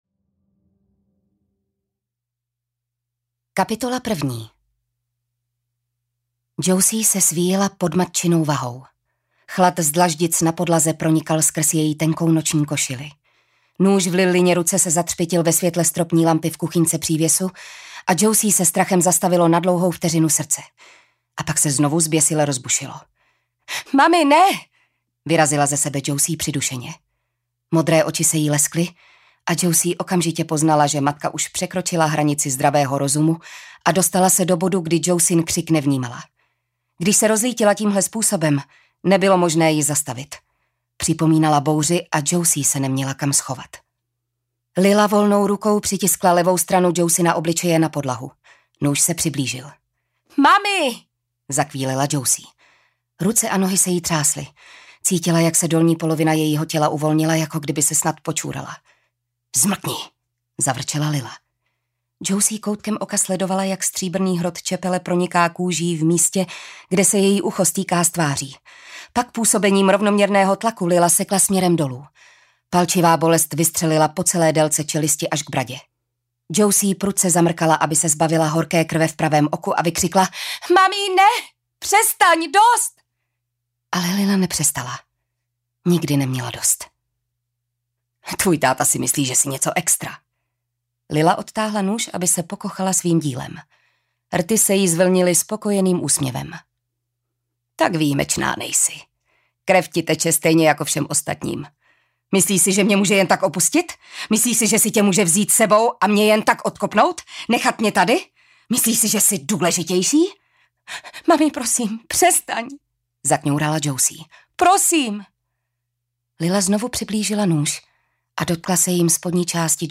Vražedná temnota audiokniha
Ukázka z knihy
• InterpretJitka Ježková